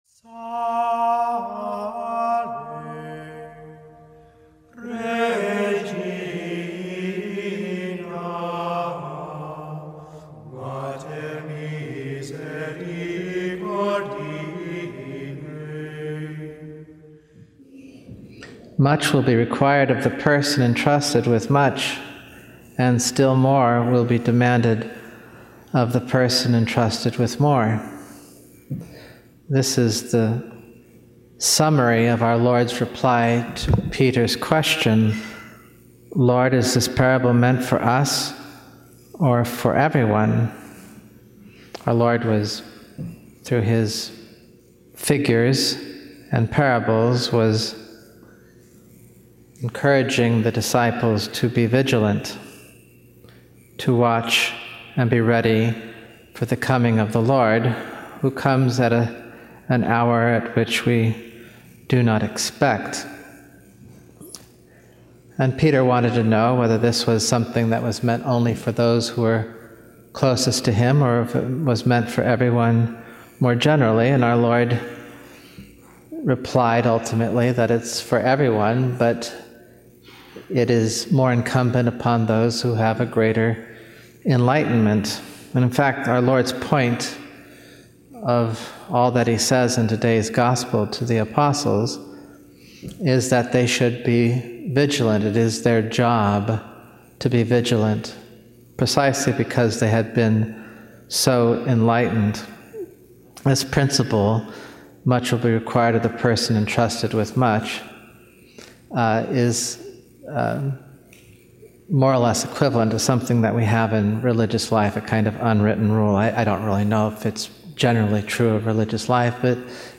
Homily
Mass: 19th Sunday in Ordinary Time - Sunday Readings: 1st: wis 18:6-9 Resp: psa 33:1, 12, 18-19, 20-22 2nd: heb 11:1-2, 8-19 Gsp: luk 12:32-48 Audio (MP3) +++